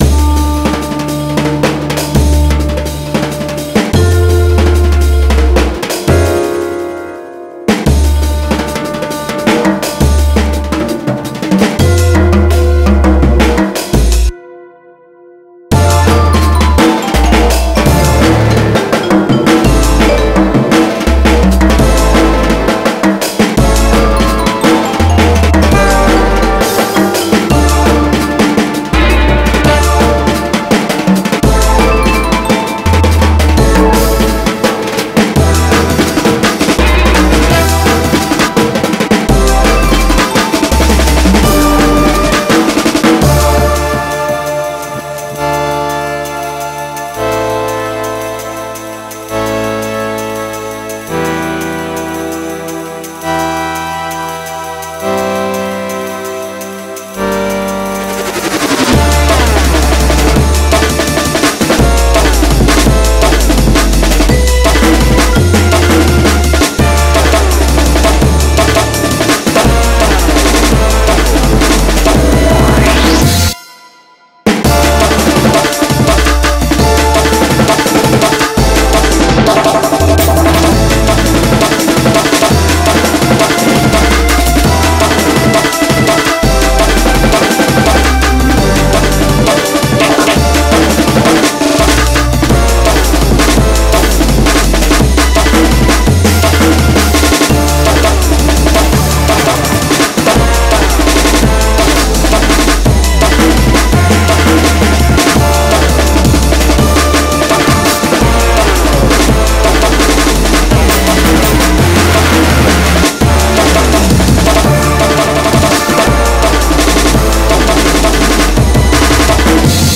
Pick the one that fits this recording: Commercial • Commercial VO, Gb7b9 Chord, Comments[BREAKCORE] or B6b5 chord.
Comments[BREAKCORE]